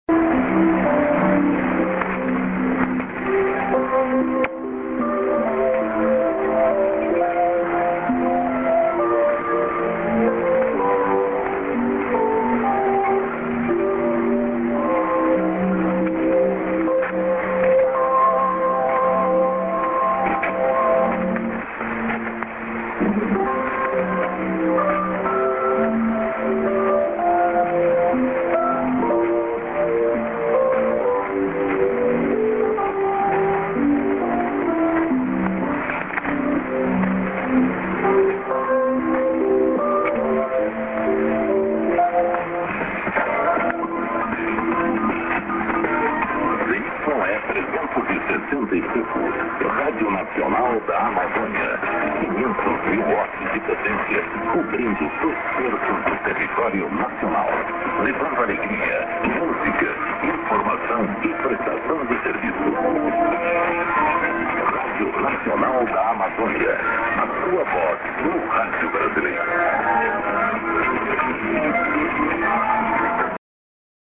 IS->ID+ADDR(men)->　＊久しぶりにＩＳが聞こえました。